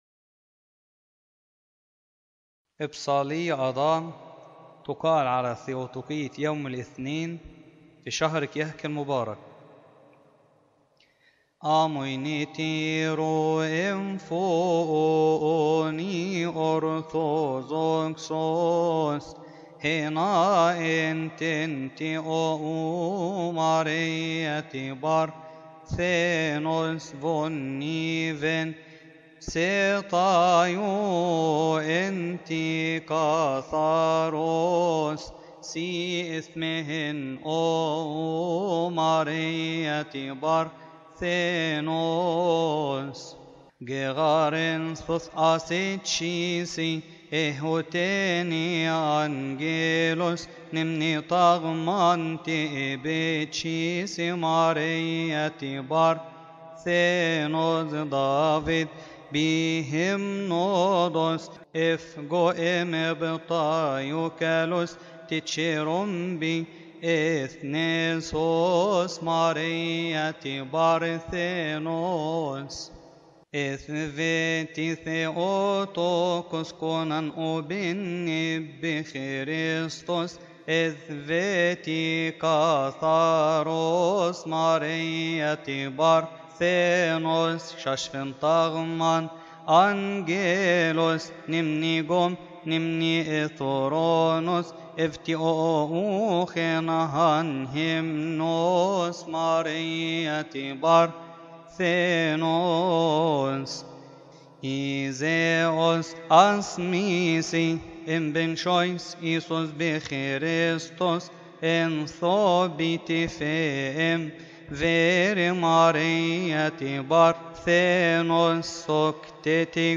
المرتل
يقال في تسبحة نصف الليل بشهر كيهك